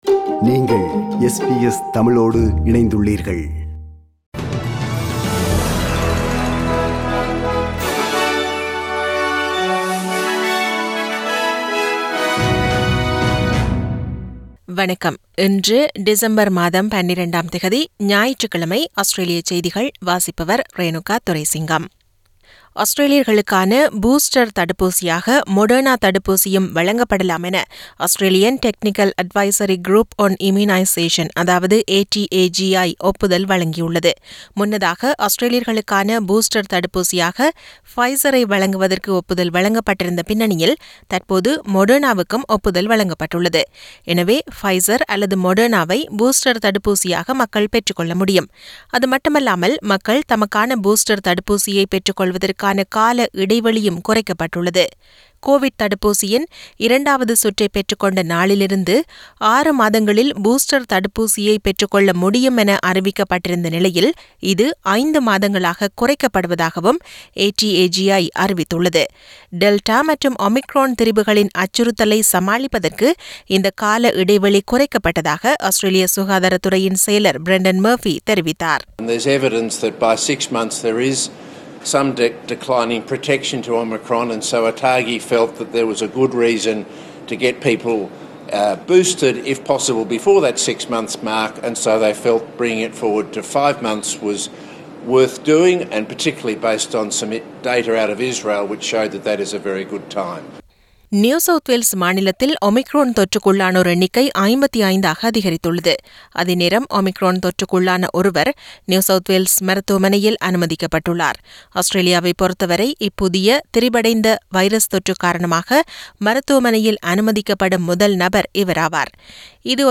Australian news bulletin for Sunday 12 December 2021.